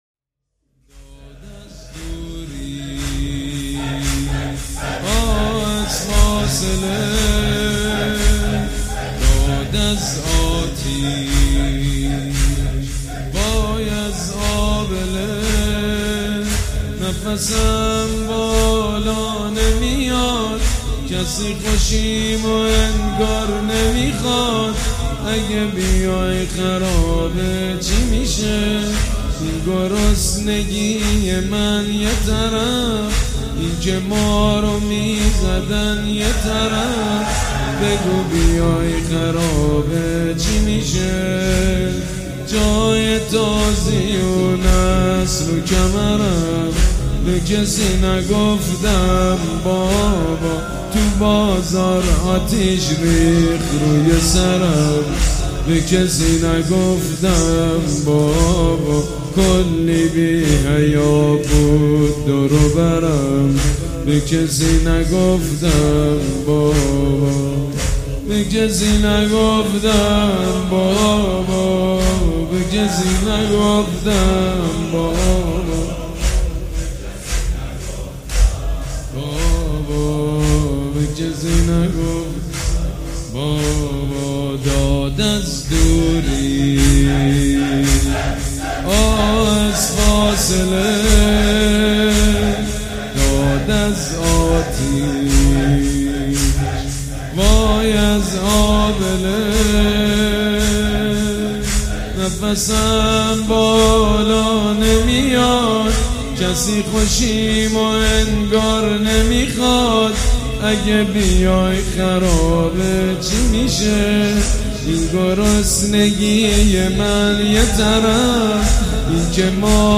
مداحی جدید
شب هجدهم ماه ‌مبارک‌ رمضان پنجشنبه 2 خرداد 1398 حسینیه ریحانة النبی